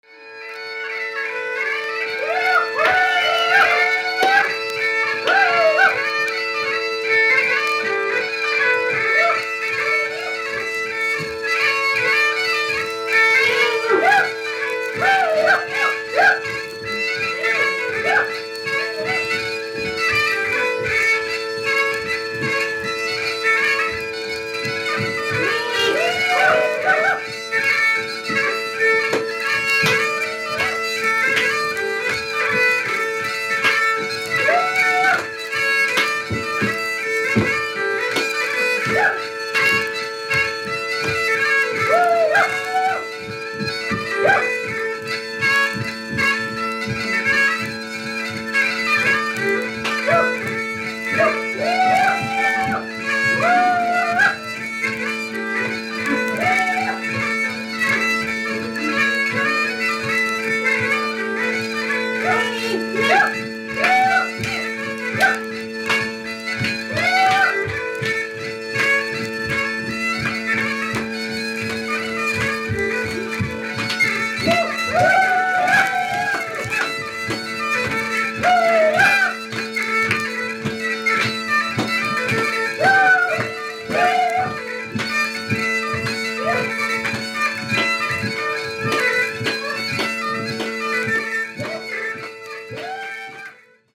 Scotland・バグパイプを使ったTraditional Music
電子音にも聞こえる不思議なサウンドに人々の声が重なります。